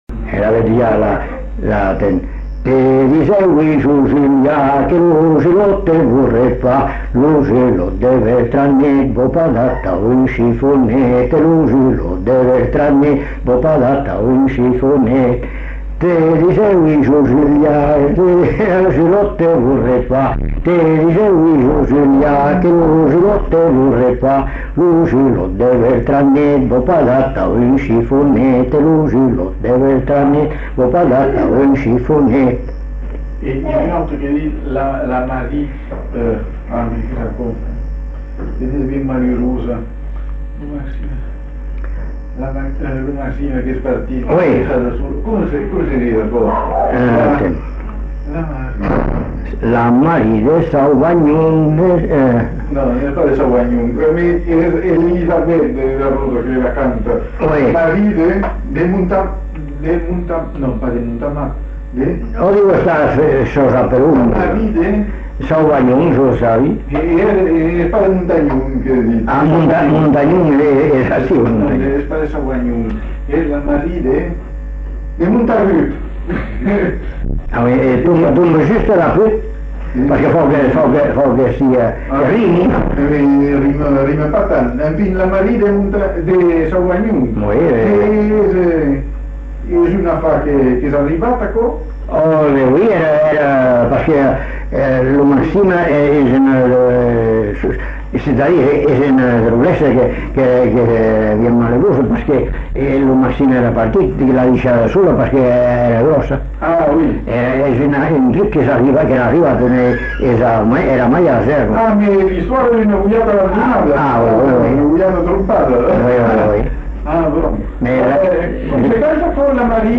Lieu : Bazas
Genre : chant
Effectif : 1
Type de voix : voix d'homme
Production du son : chanté
Danse : rondeau